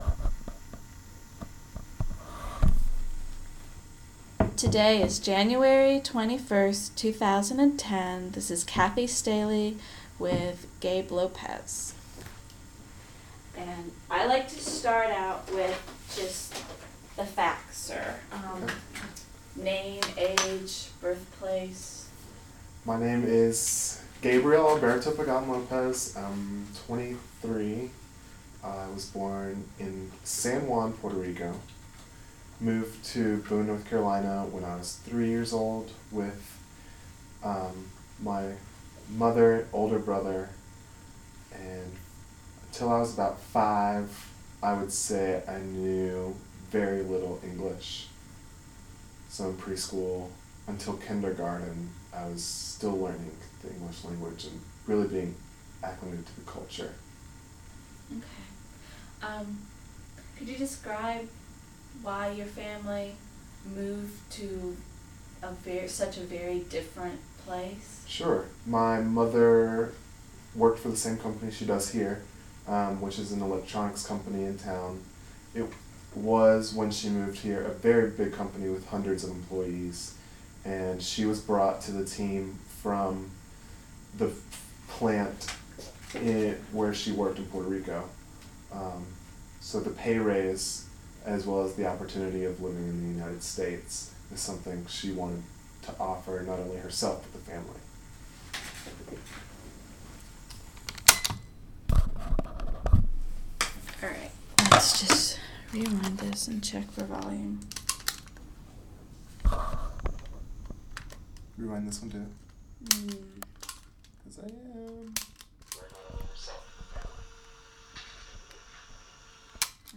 Appalachian Lesbian, Gay, Bisexual, and Transgender Oral History Project